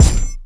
Kick 5.wav